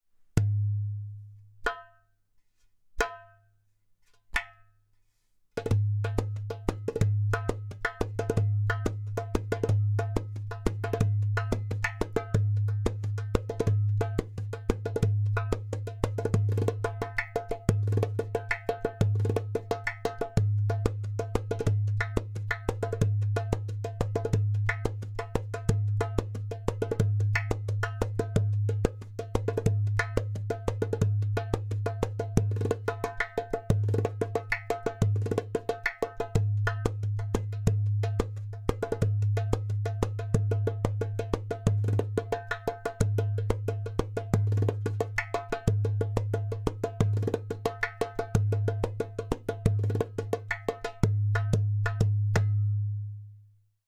• Strong and easy to produce clay kik (click) sound
• Beautiful harmonic overtones.
• Thin goat skin (0.3mm)
• Body: Ceramic / Clay